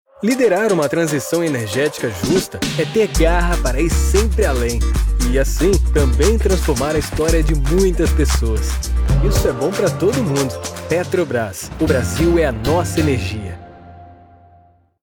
Male
I have the experience to create a versatile and natural interpretation just the way your project needs it with a youthful, friendly and kind voice.
Television Spots
Words that describe my voice are youthful, Conversational, friendly.